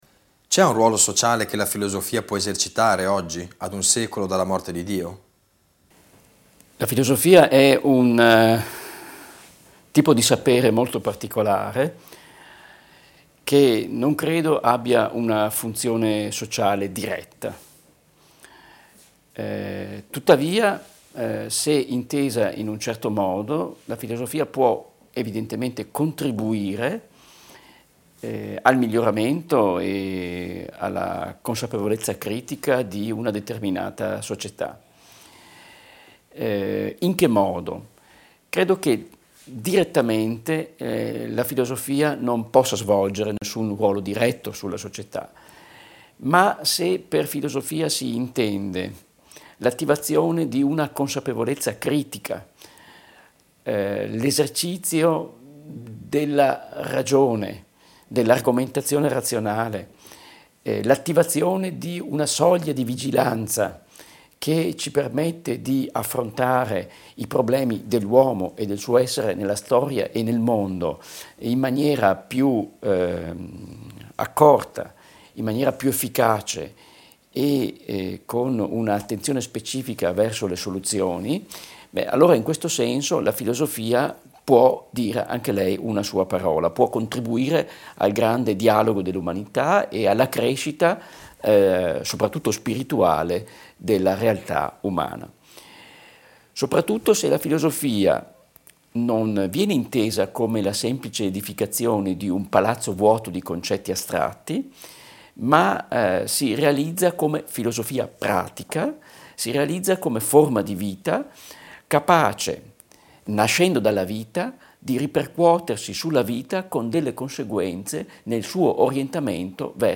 Seconda parte dell'intervista a Franco Volpi, esperto di Heidegger e curatore della nuova edizione di "Essere e Tempo"